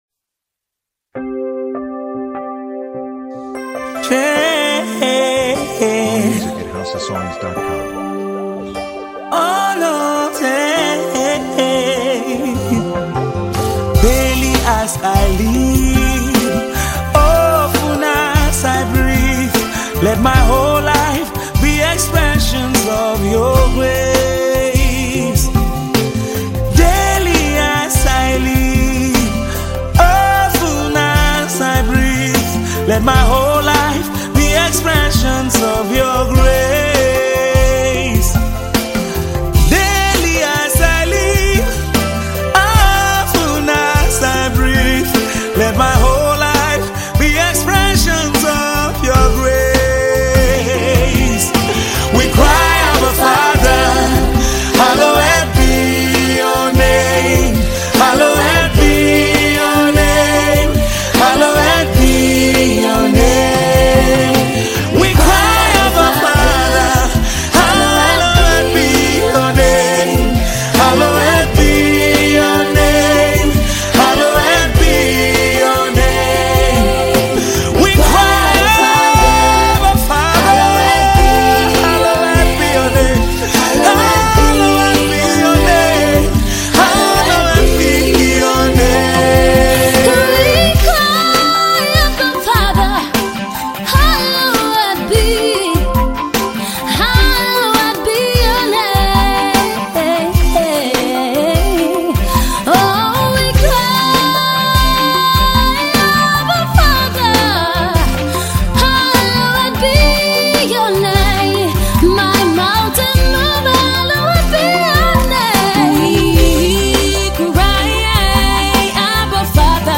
Tiv Song